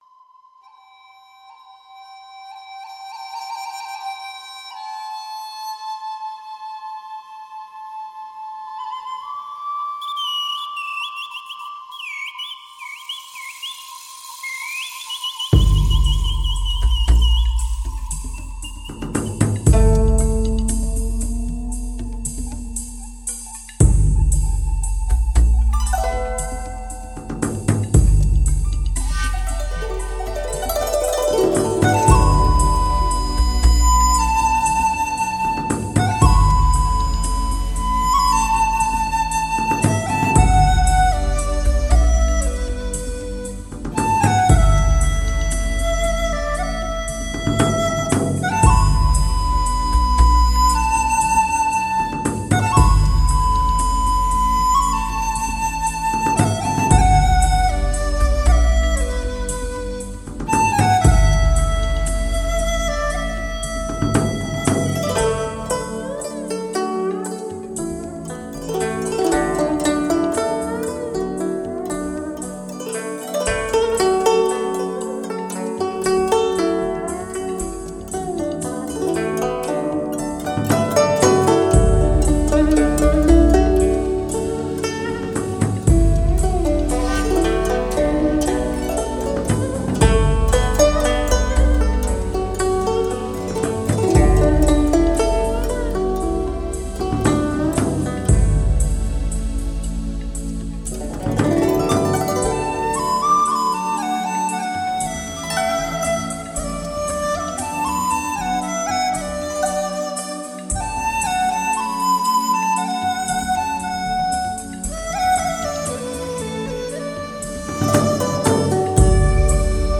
那是因为纯美的音符，温馨而悠远，跳动在青春梦境。
纯音乐的世界，心事化作音符，纵情倾诉着守望已久的幸福！